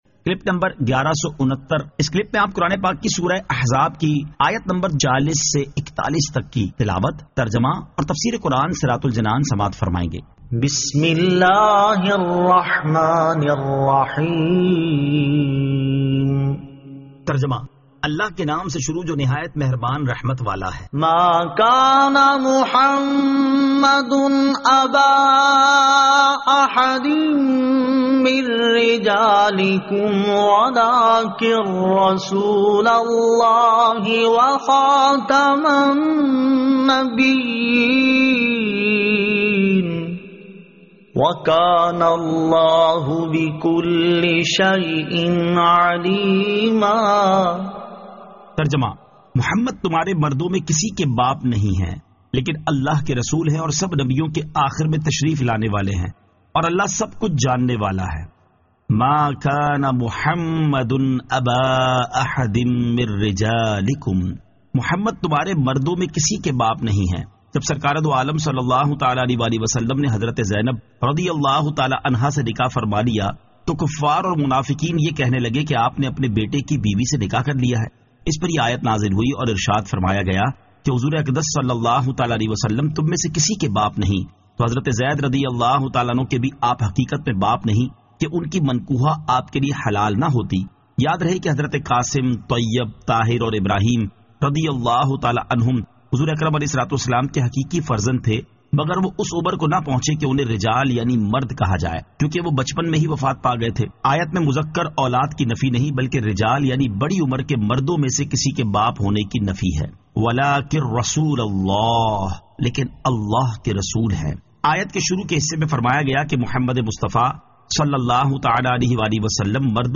Surah Al-Ahzab 40 To 41 Tilawat , Tarjama , Tafseer